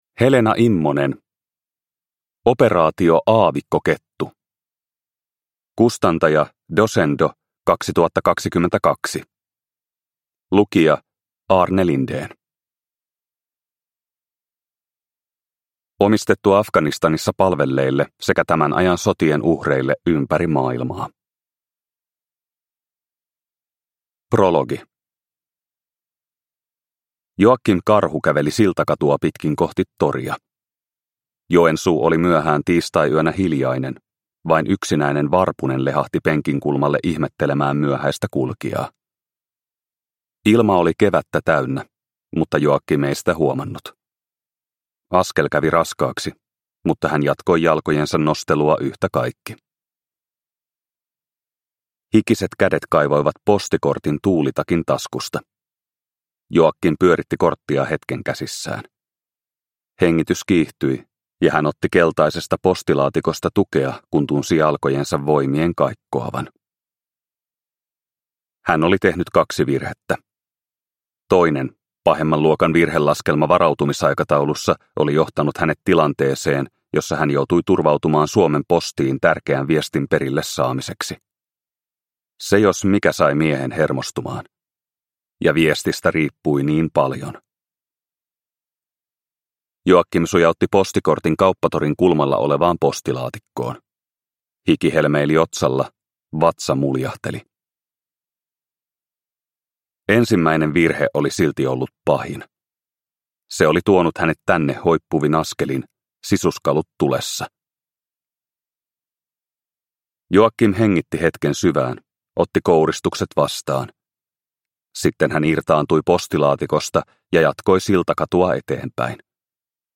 Operaatio Aavikkokettu – Ljudbok – Laddas ner